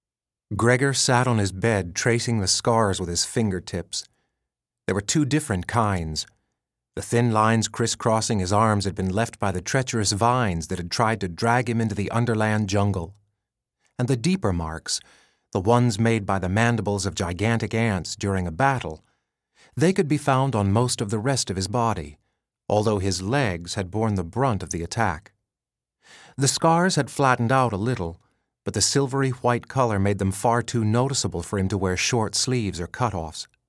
Marks-of-Secret-Audio-Book-Sample.mp3